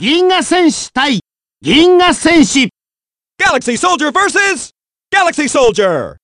As I've done with all DBZ games in recent history, here is a complete list of character names that the announcer speaks aloud in the "[insert name here] versus [insert name here]" fashion.
In the secondary set of announcements for each language, we have one additional name that has not previously appeared: Ginga-Senshi / Galaxy Soldier.